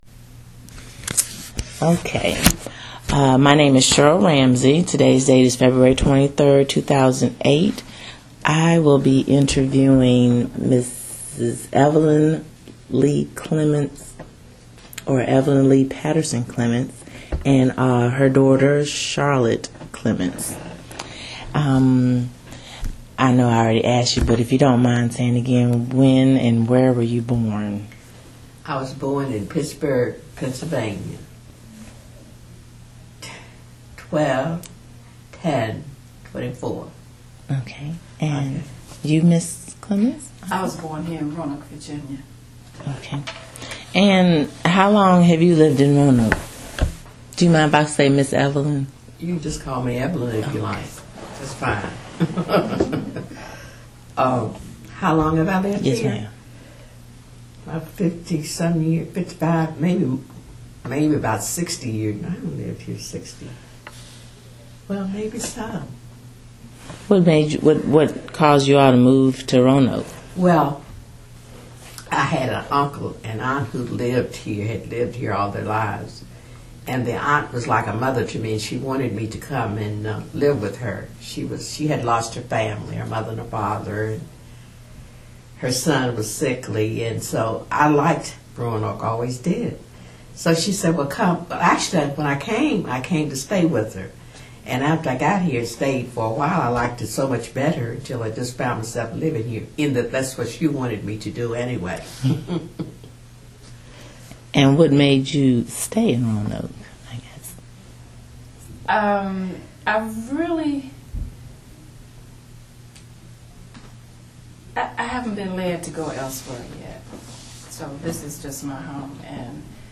Location: Jerusalem Baptist Church
Neighborhood Oral History Project